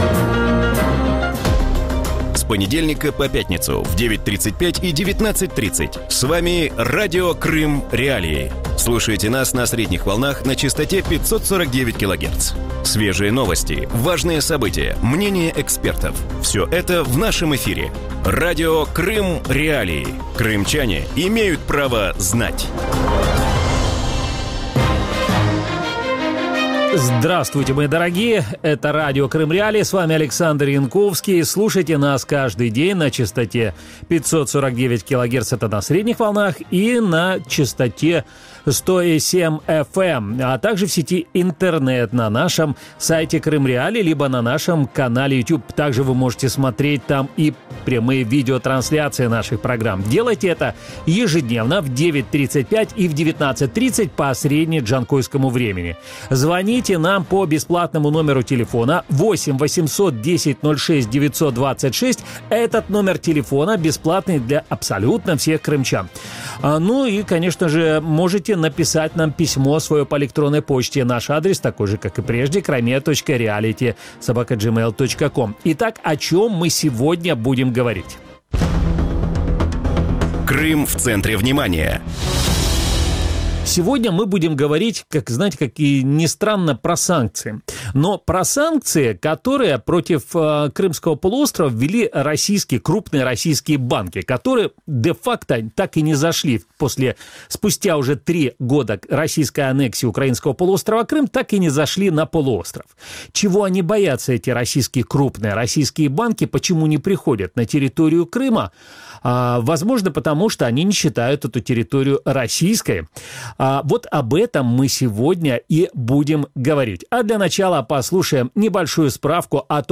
У вечірньому ефірі Радіо Крим.Реалії говорять про неухильне зниження кількості банків у анексованному Криму.